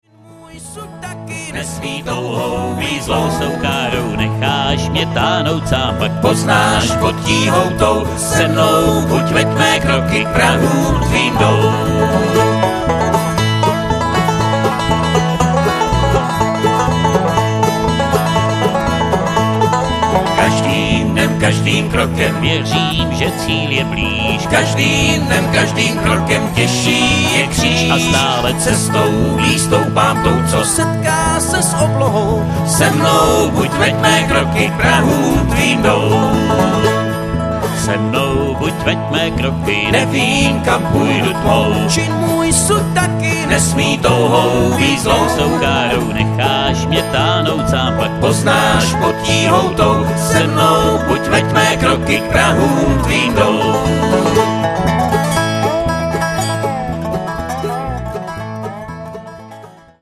Guitar
Banjo
Mandolin
Dobro
Electric Bass